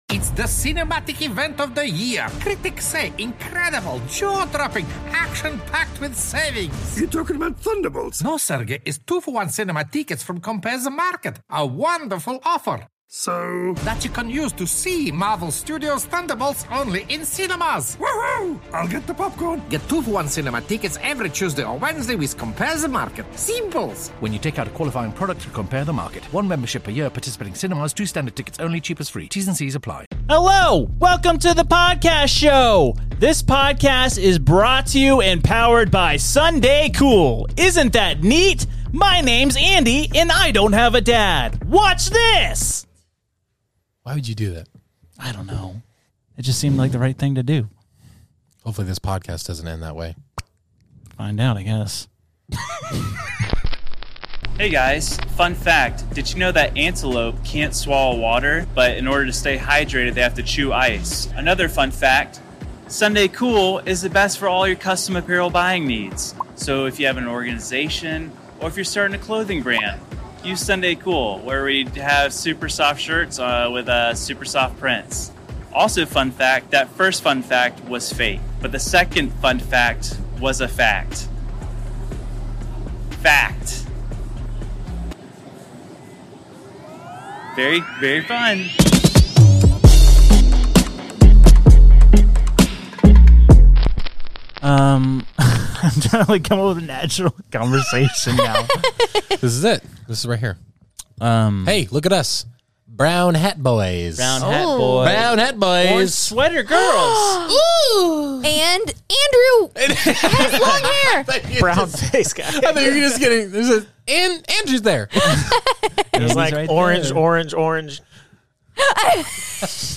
You know the episode is gonna be great when it starts off with a song so beautiful that it gets flagged for copyright infringement!